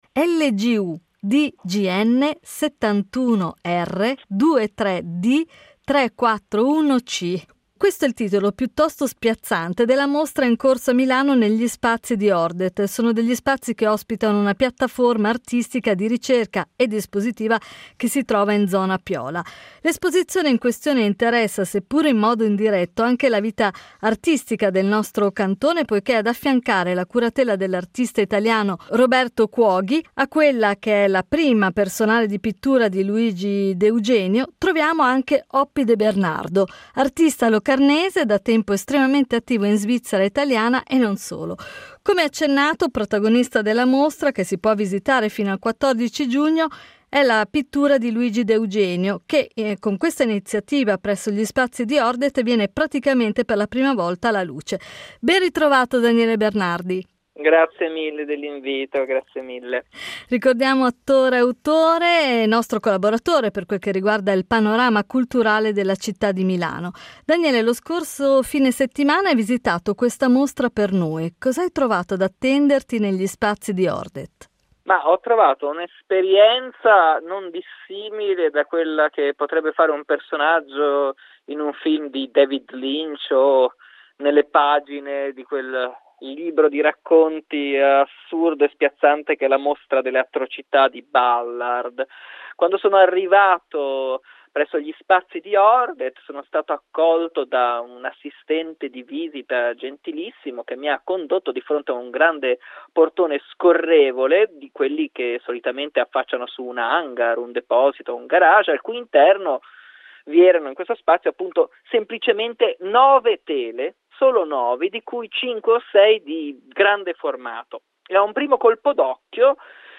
La recensione